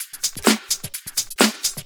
Index of /VEE/VEE Electro Loops 128 BPM
VEE Electro Loop 320.wav